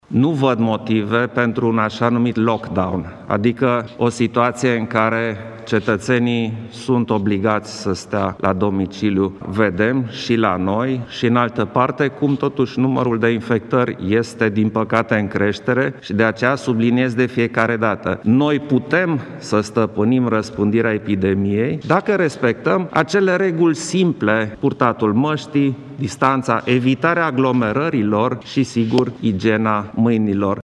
Într-o conferință de presă de la Palatul Cotroceni, Klaus Iohannis a susținut că în acest moment nu sunt întrunite condițiile pentru ca România să reintre în carantină.